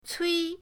cui1.mp3